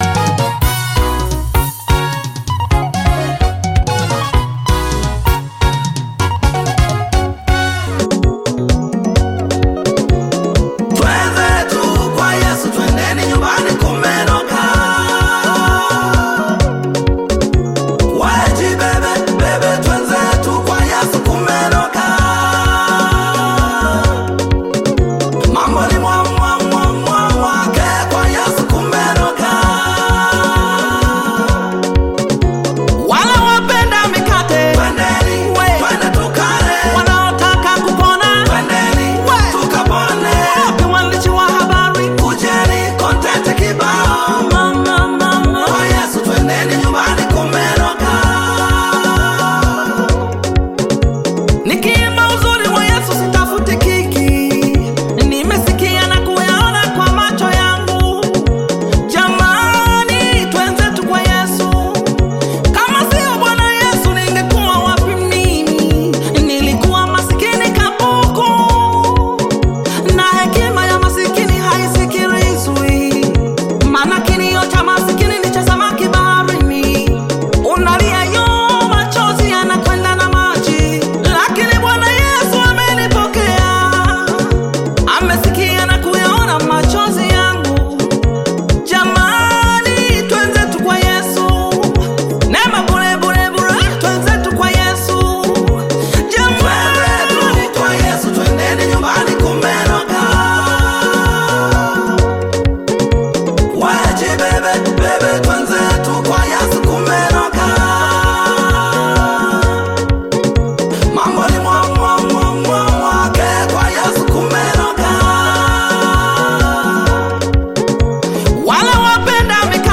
Tanzanian Gospel
Gospel song